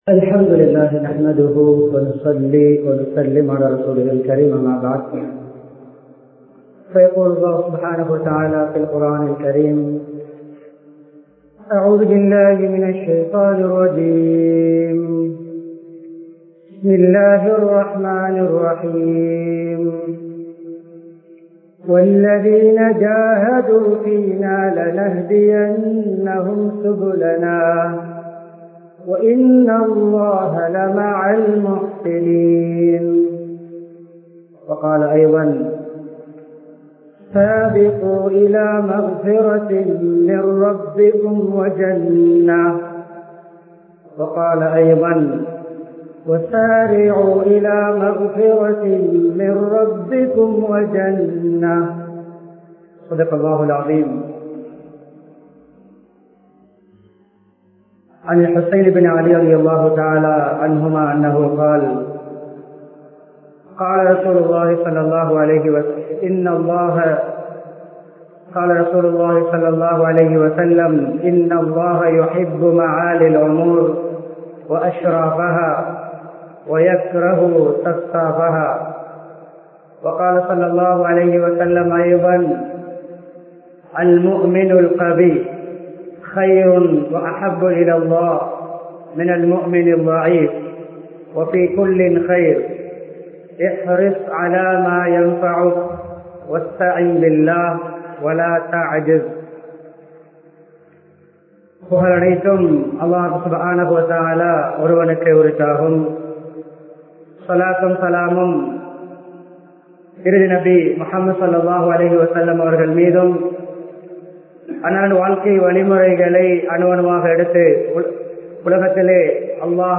இலட்சியம் வெற்றிக்கான திறவுகோல் | Audio Bayans | All Ceylon Muslim Youth Community | Addalaichenai
Muhiyaddeen Grand Jumua Masjith